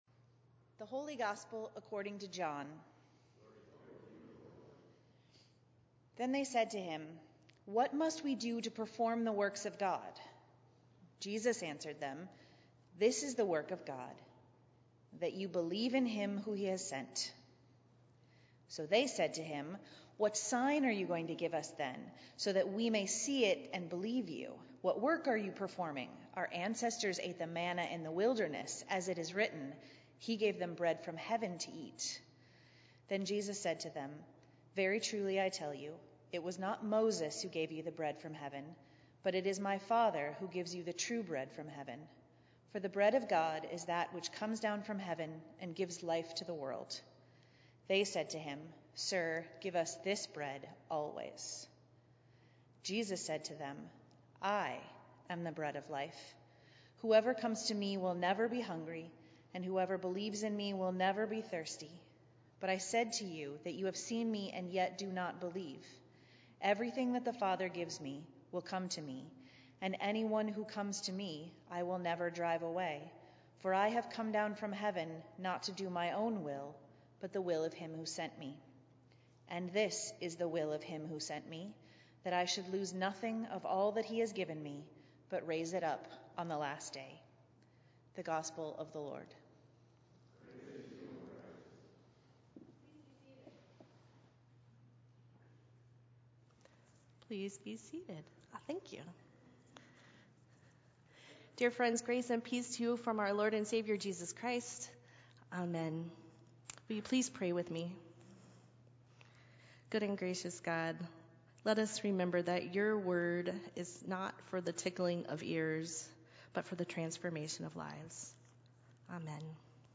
Minnetonka Livestream · Sunday, February 20, 2022 9:30 am